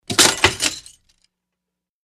Stepping on picture frame, crunchy glass